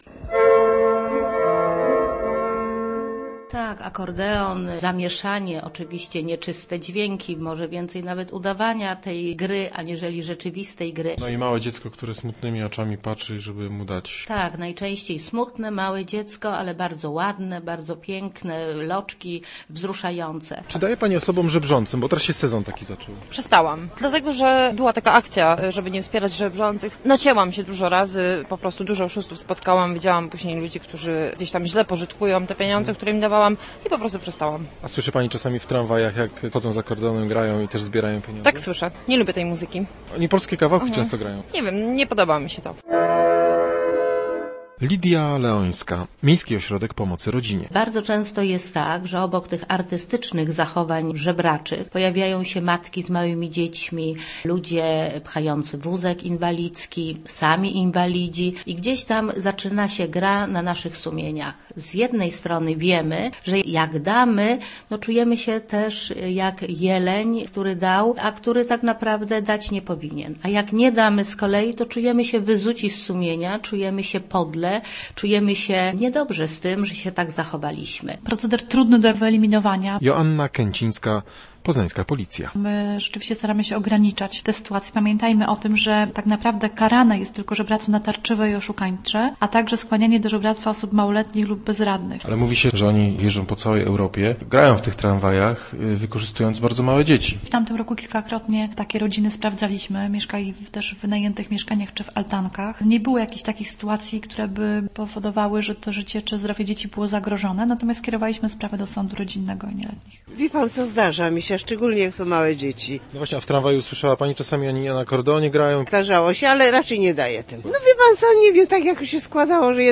afohdpeof49z579_akordeon_tramwaju.mp3